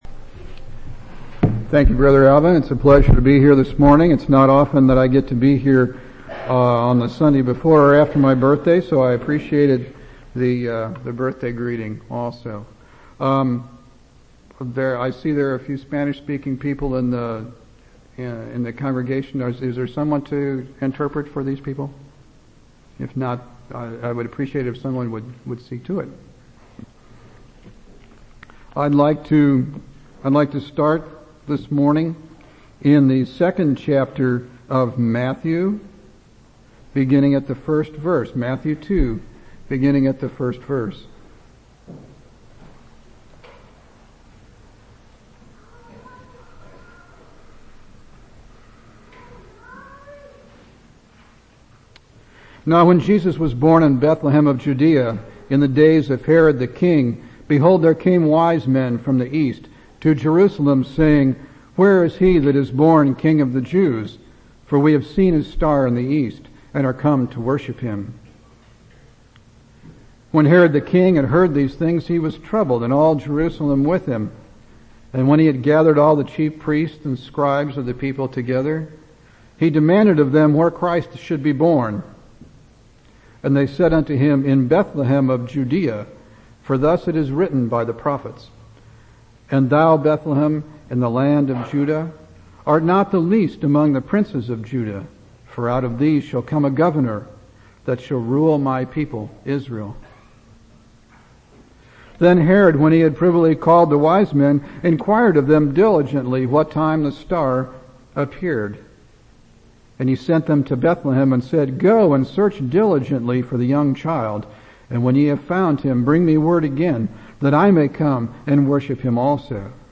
6/29/2003 Location: Temple Lot Local Event